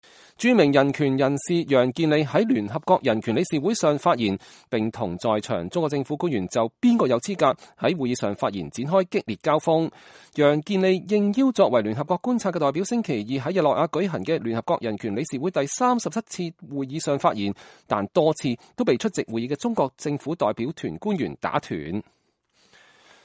楊建利2018年3月20日在聯合國人權理事會會議上發言 (聯合國觀察照片)